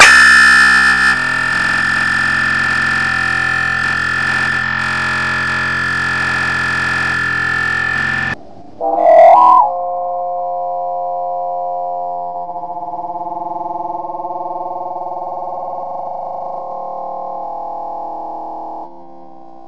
EVP Electronic Voice Phenomena
Either way, it is still quite exciting ....as there were no dogs in the woods. So ghost dog or machine - You decide.
maxandwoodsbarks.wav